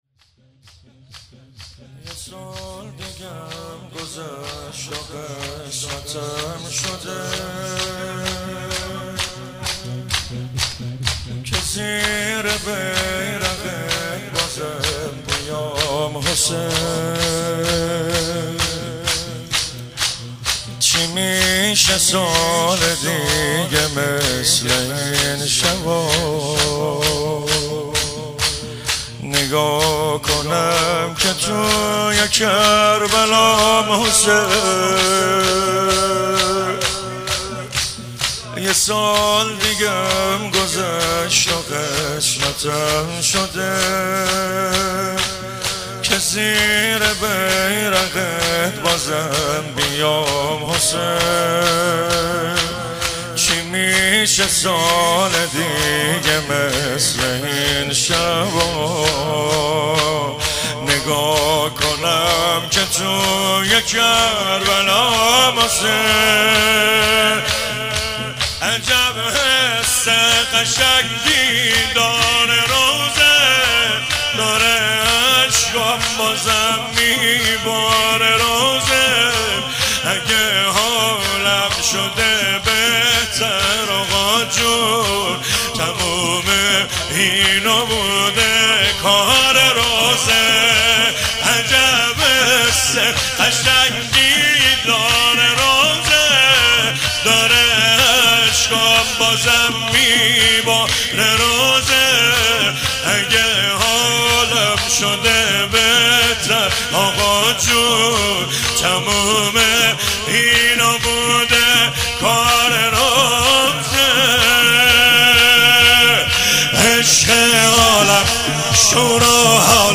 محرم 98